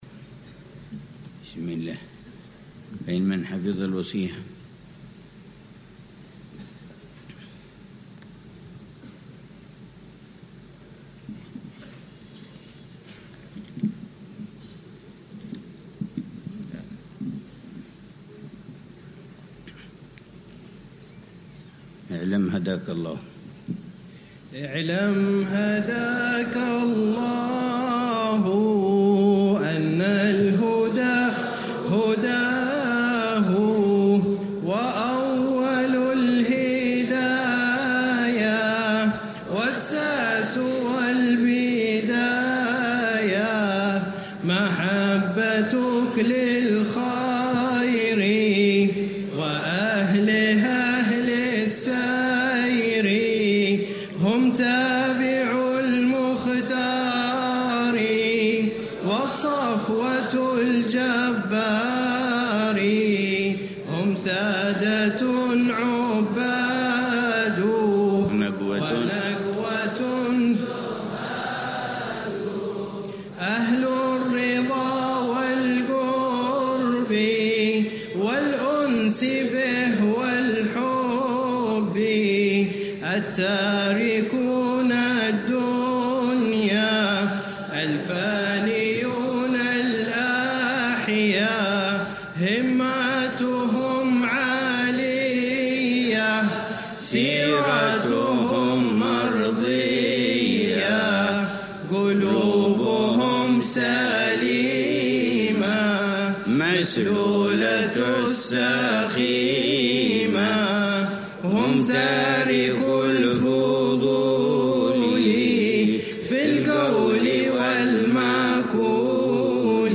شرح الحبيب عمر بن حفيظ على منظومة «هدية الصديق للأخ والرفيق» للحبيب عبد الله بن حسين بن طاهر. الدرس التاسع (20 محرم 1447هـ)